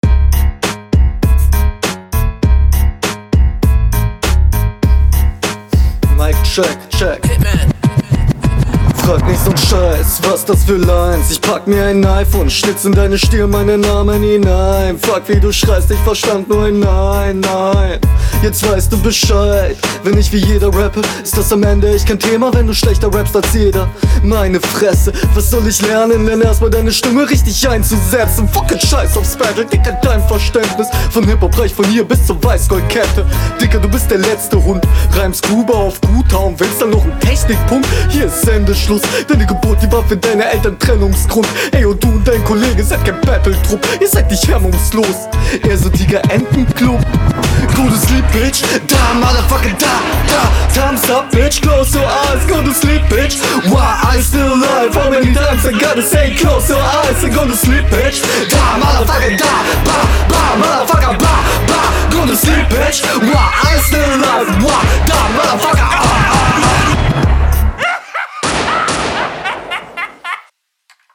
Gut einiges gekontert und interessanter Stil.